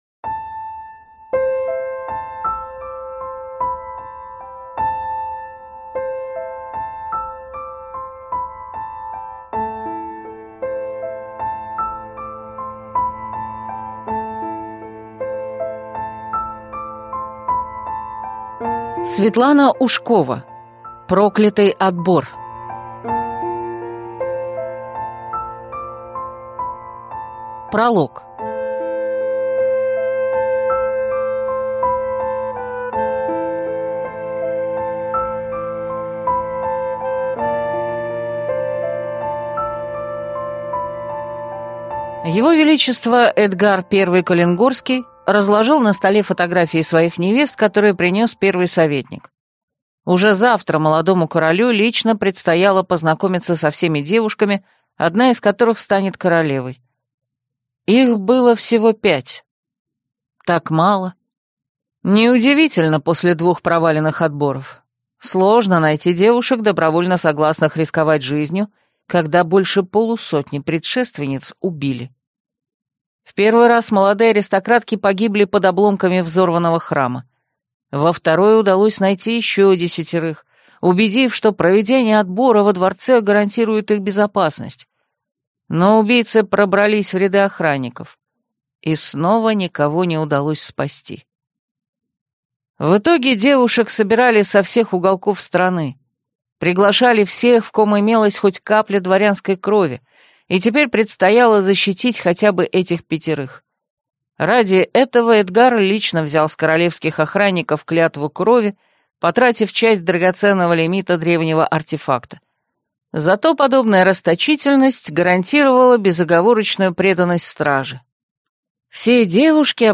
Аудиокнига Проклятый отбор | Библиотека аудиокниг
Прослушать и бесплатно скачать фрагмент аудиокниги